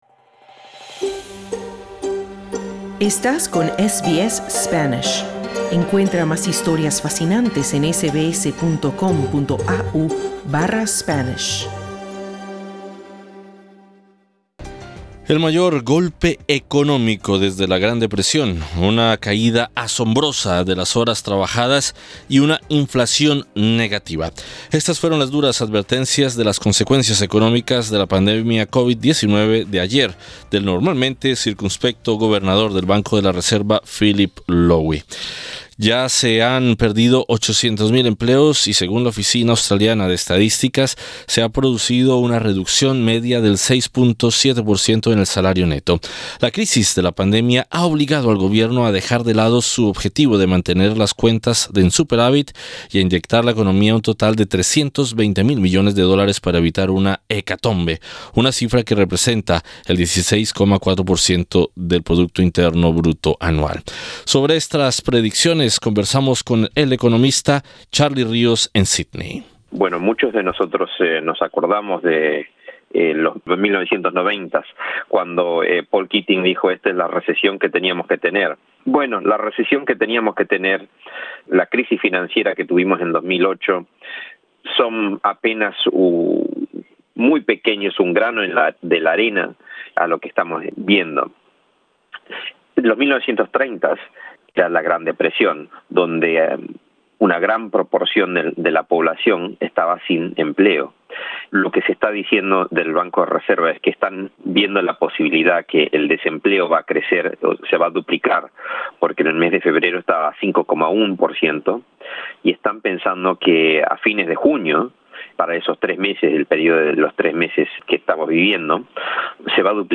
Sobre estas predicciones conversamos con el economista